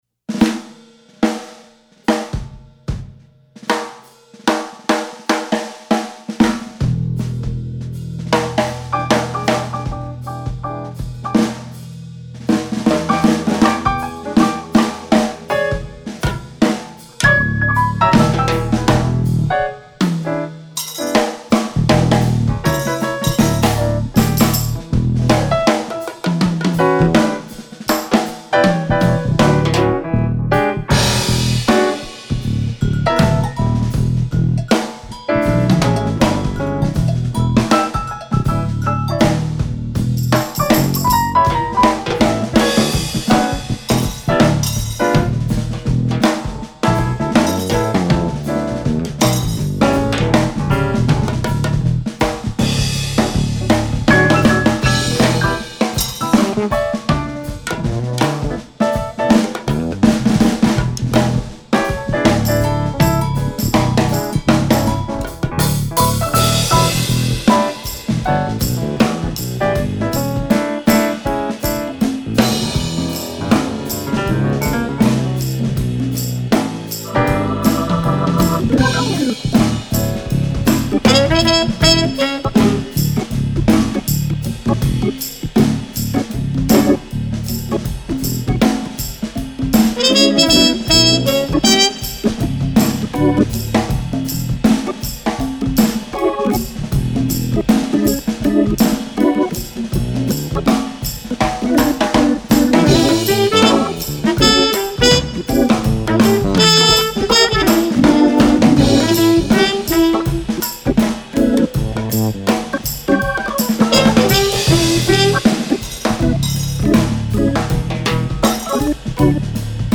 Fretless Bass, Tenor Saxophone
Piano, Organ